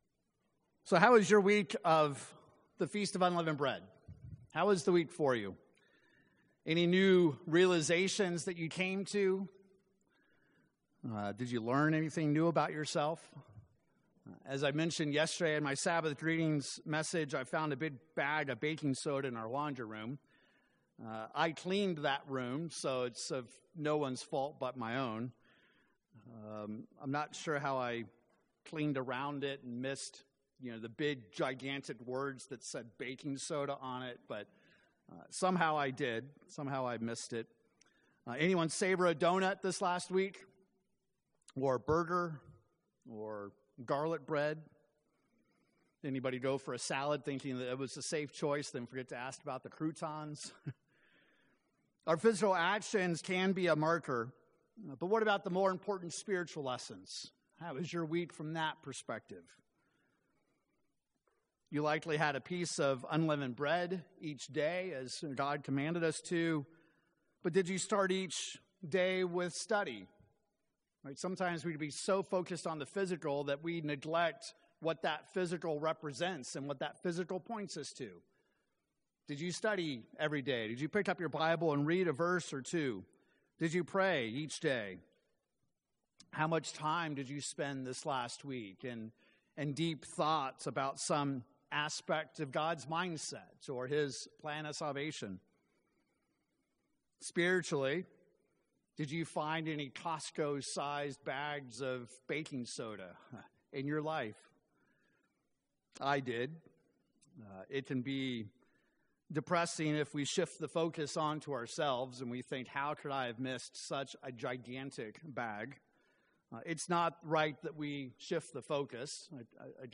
In this sermon, we examine the unique 7th Day of the Feast of Unleavened Bread and discover the meaning in this Holy Day. In the end, we realize that through our obedience, God has always been there, is still there, and will always be there for us.